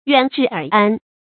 遠至邇安 注音： ㄧㄨㄢˇ ㄓㄧˋ ㄦˇ ㄢ 讀音讀法： 意思解釋： 遠方來歸附，近民安居樂業。形容政治清明，國家大治。